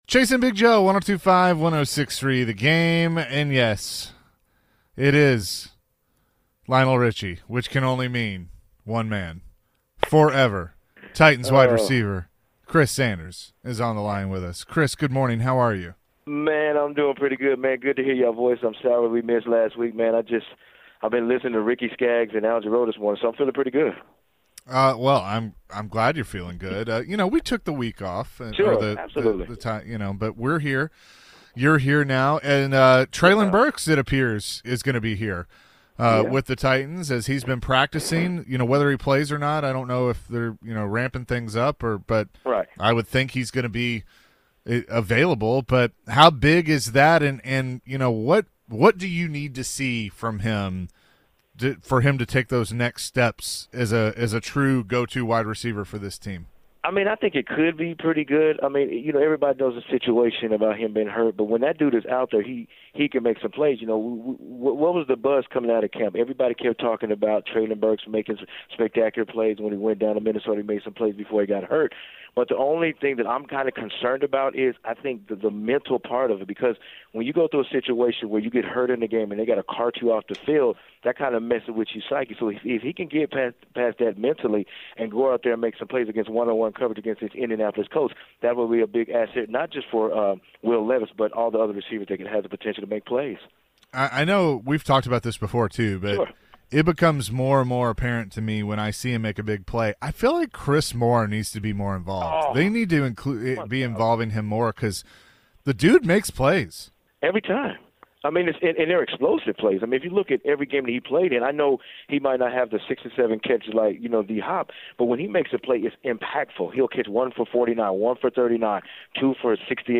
Forever Titans wide receiver Chris Sanders joined the show and shared his thoughts about the Titans offense and their upcoming game against the Colts. What can Titans fans expect from Treylon Burks now that he is back and practicing?